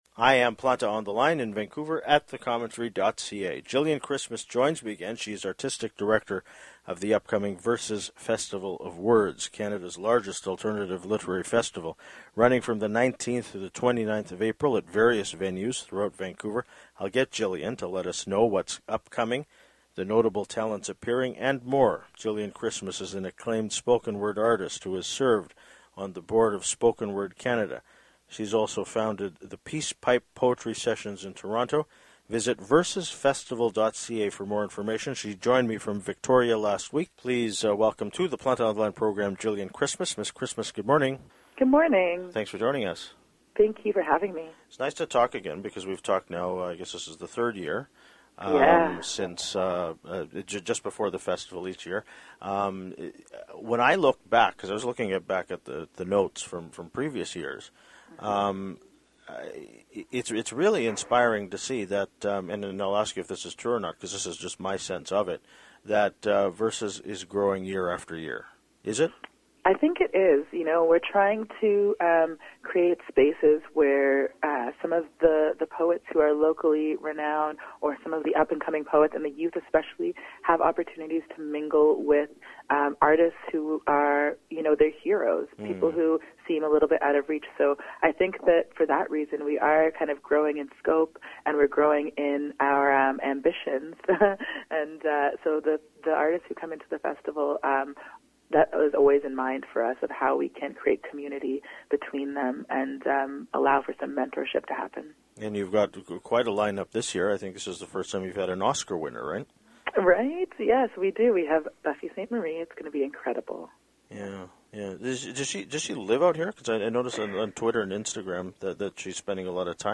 She joined me from Victoria, last week.